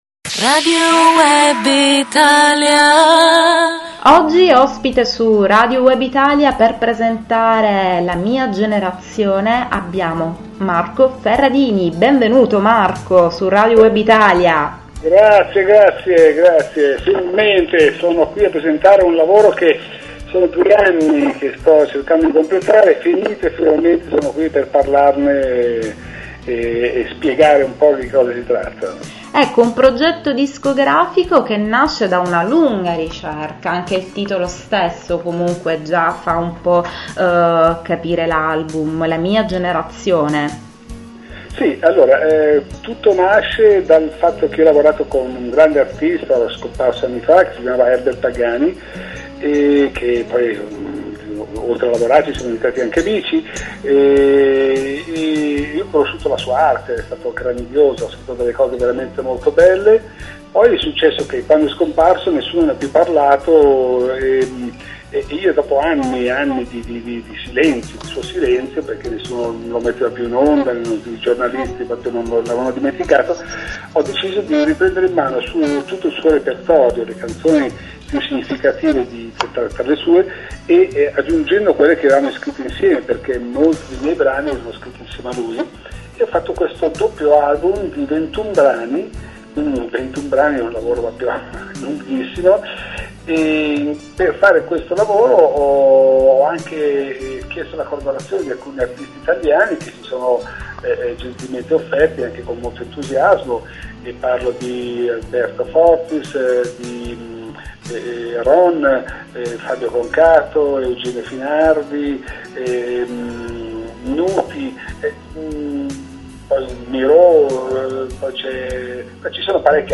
Marco Ferradini : intervista su Radio Web Italia - Radio Web Italia
Marco-Ferradini-Intervista-3.mp3